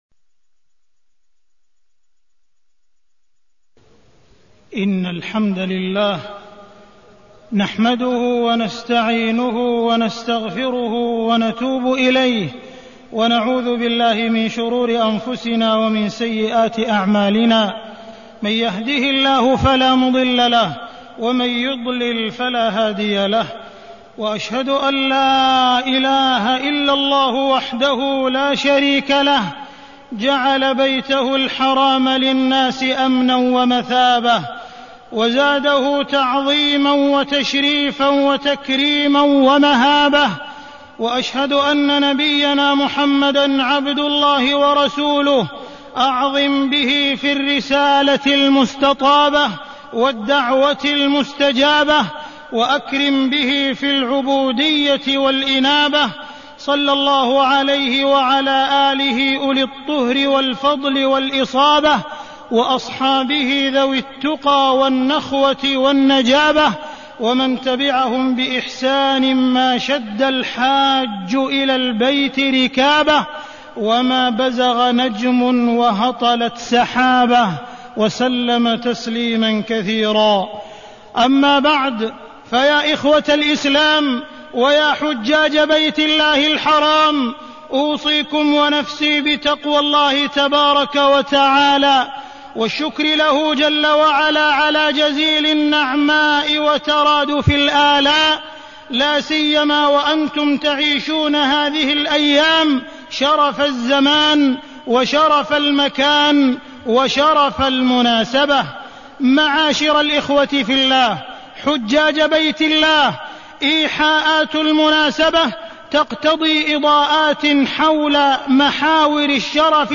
تاريخ النشر ٧ ذو الحجة ١٤٢١ هـ المكان: المسجد الحرام الشيخ: معالي الشيخ أ.د. عبدالرحمن بن عبدالعزيز السديس معالي الشيخ أ.د. عبدالرحمن بن عبدالعزيز السديس الحج إلى بيت الله الحرام